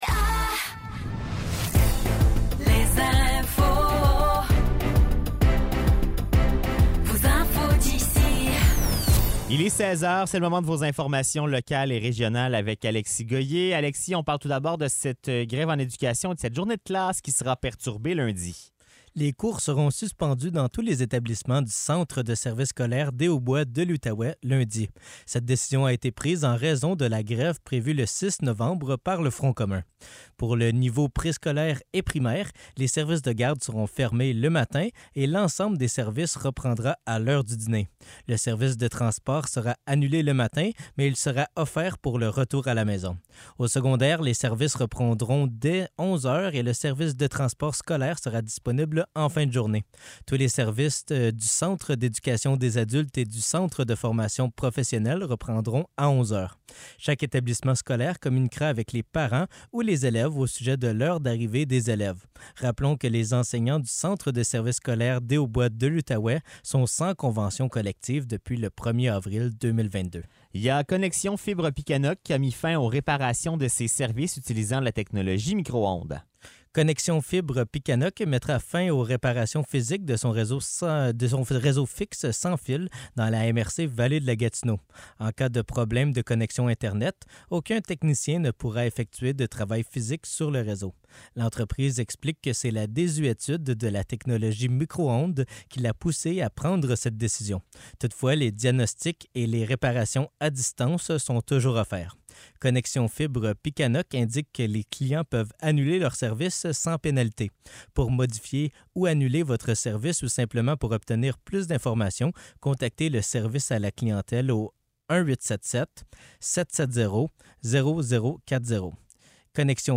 Nouvelles locales - 2 novembre 2023 - 16 h